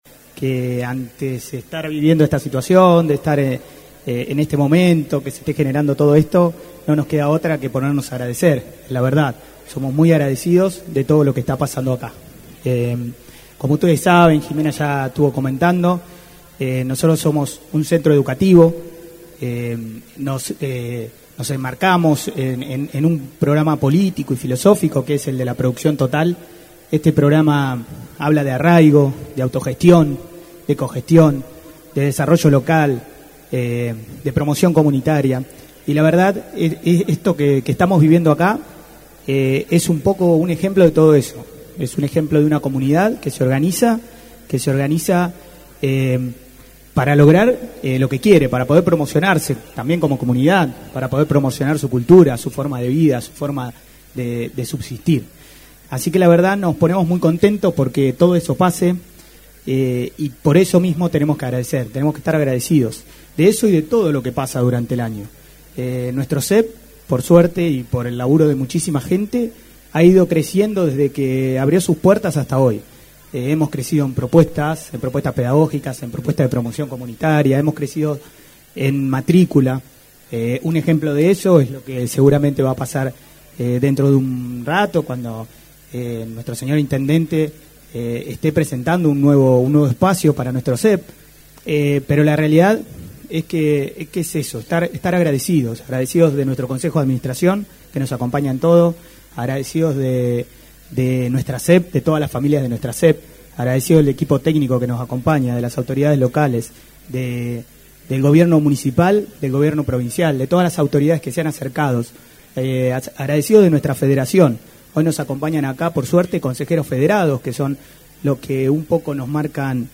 En lo protocolar, se escuchó la reflexión de: